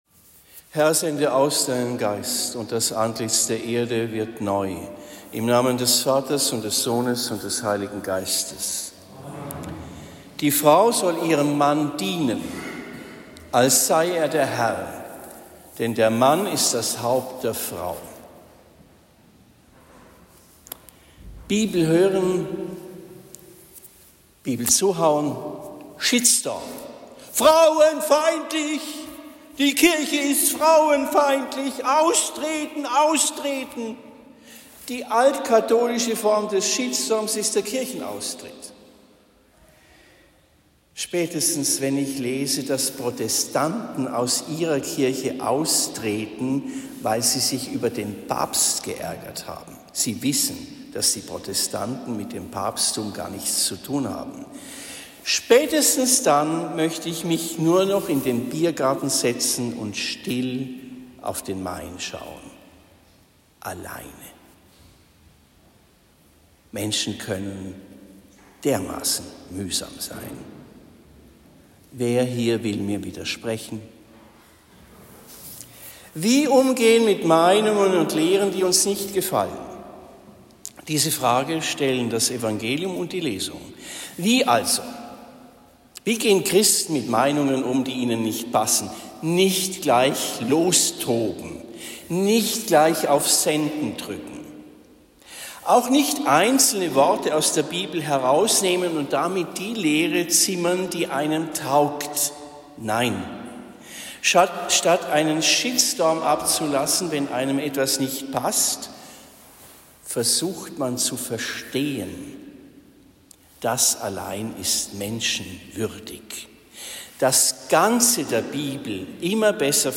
Predigt am 25. August 2024 in Homburg St.-Burkhard